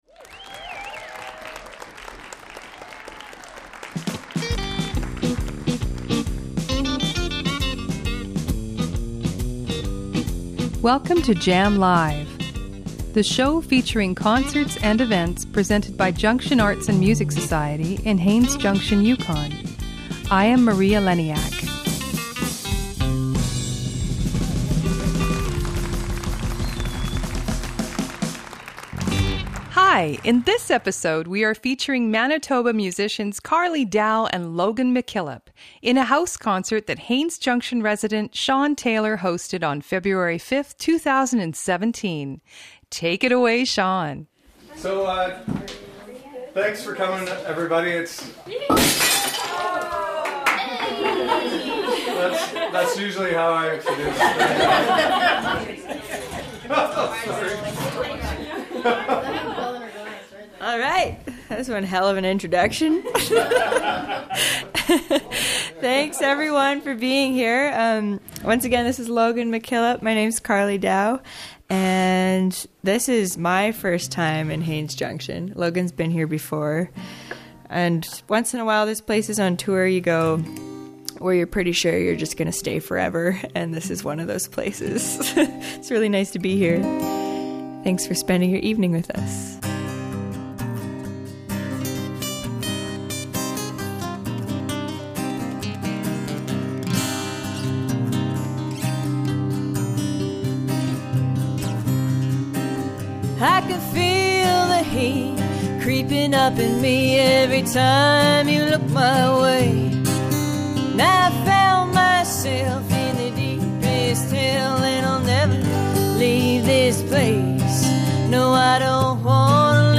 Live music recorded in Haines Junction, Yukon.